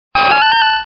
Archivo:Grito de Girafarig.ogg
actual17:35 26 oct 20140,9s (14 kB)PoryBot (discusión | contribs.)Actualizando grito en la sexta generación (XY)